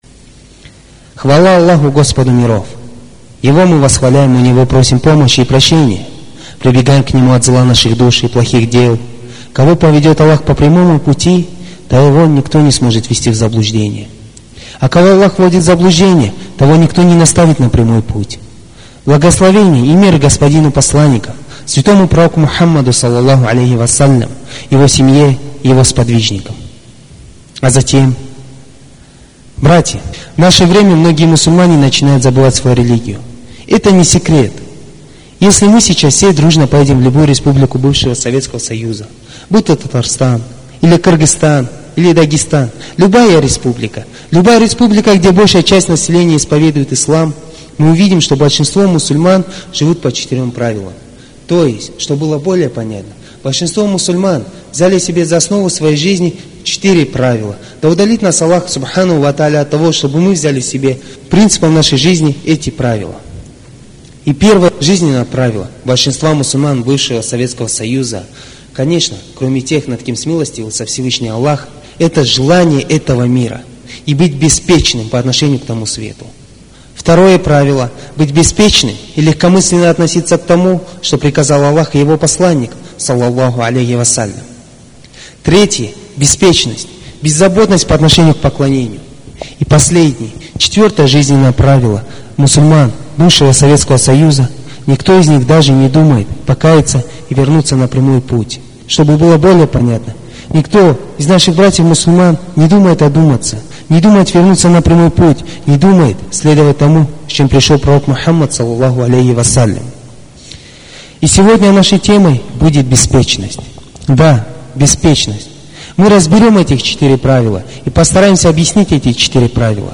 Материал: лекция шейха Мухаммада Хассана